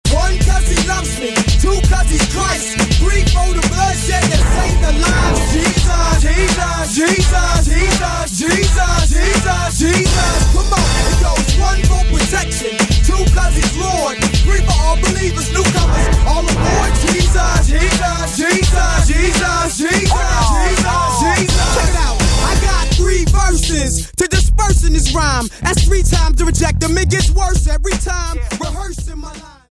STYLE: Hip-Hop
self-described as hip-hop rhythm & praise (RnP)